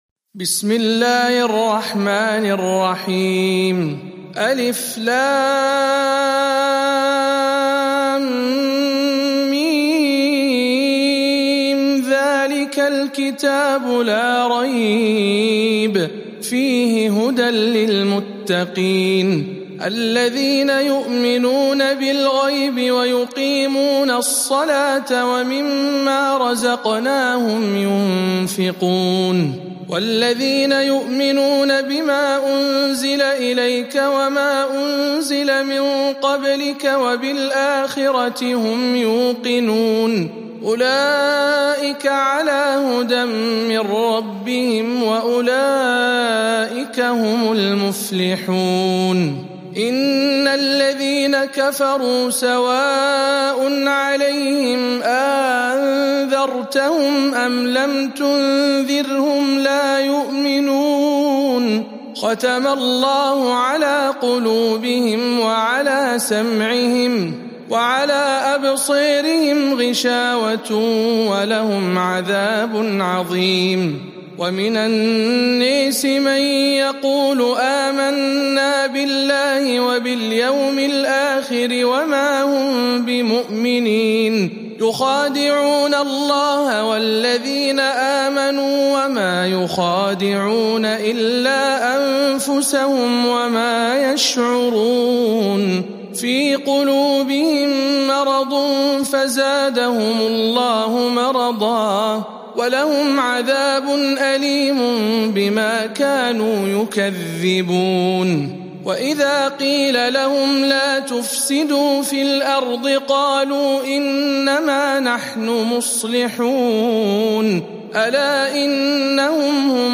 02. سورة البقرة برواية الدوري عن أبي عمرو